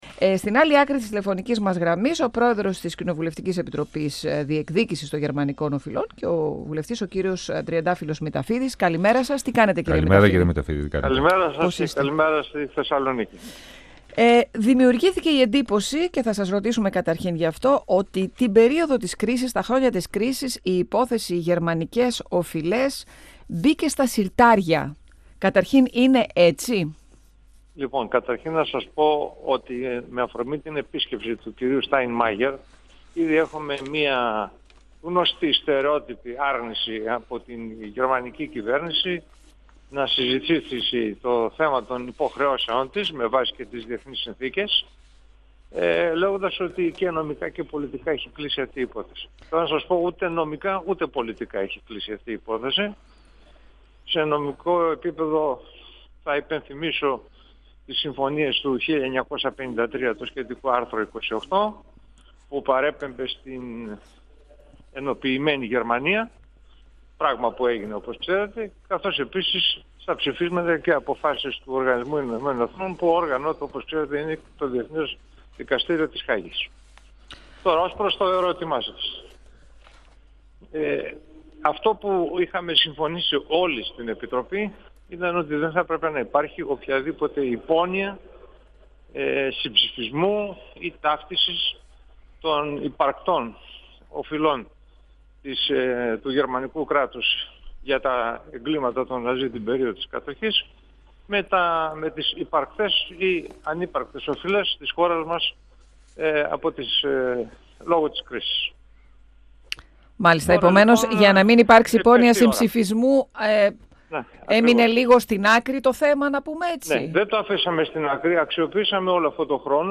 Μέχρι το τέλος του έτους θα είναι έτοιμο το πόρισμα της Ελληνικής Βουλής αναφορικά με τις αποζημιώσεις, ανέφερε μιλώντας στον 102FM του Ραδιοφωνικού Σταθμού Μακεδονίας της ΕΡΤ3 ο πρόεδρος της κοινοβουλευτικής Επιτροπής Διεκδίκησης και βουλευτής του ΣΥΡΙΖΑ, Τριαντάφυλλος Μηταφίδης και επεσήμανε την ανάγκη διεθνοποίησης του ζητήματος.